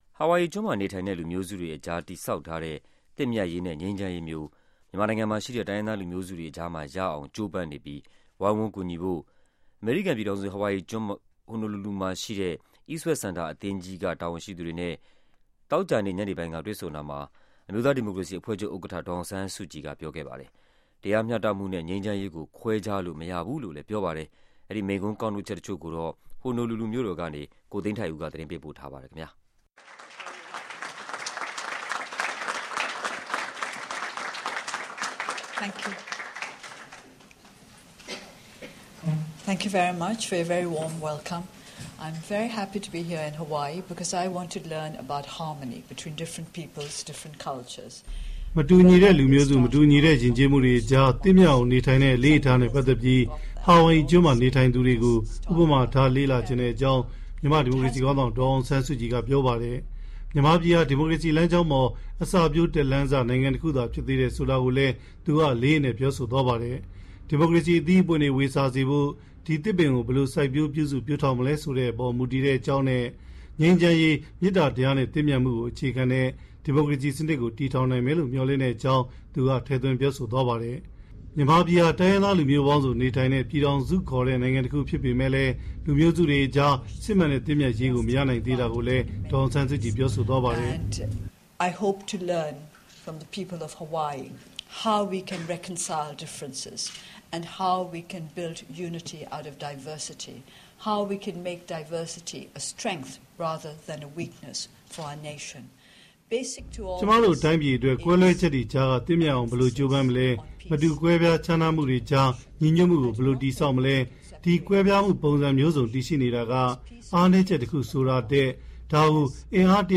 DASSK's speech, Hawaii